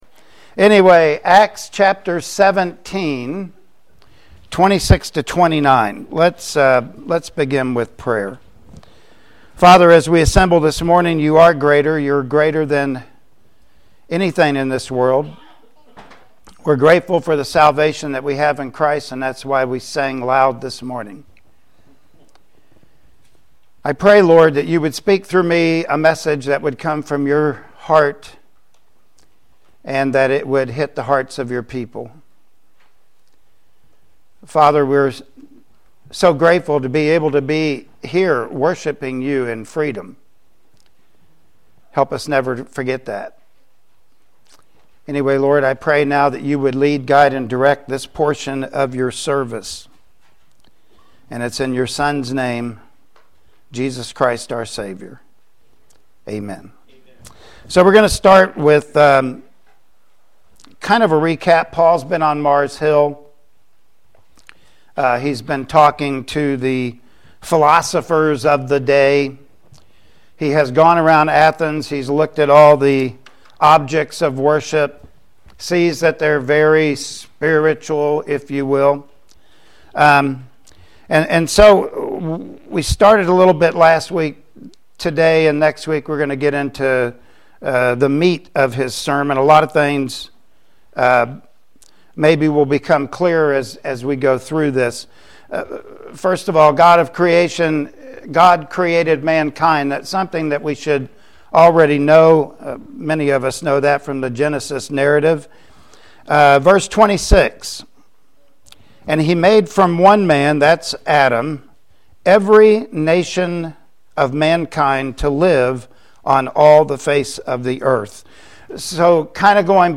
Acts 17:26-29 Service Type: Sunday Morning Worship Service Topics: Creationism